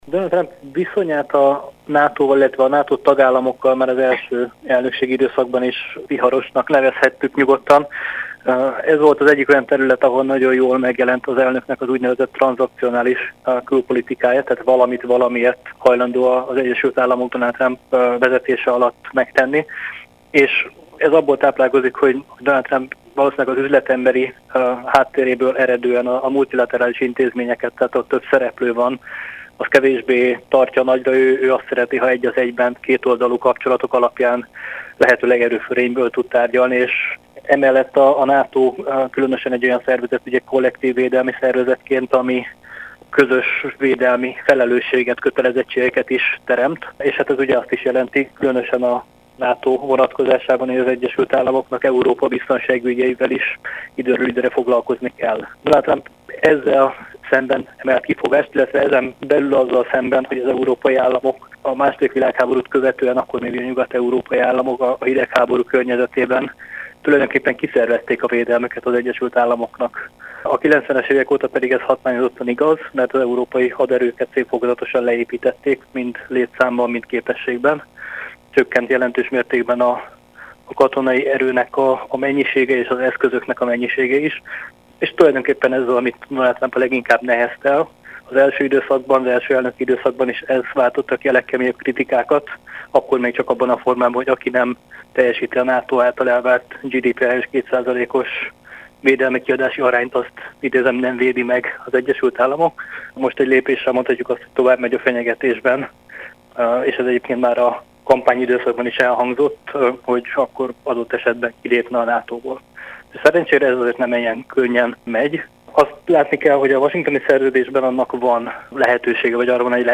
NATO-szakértőt kérdezte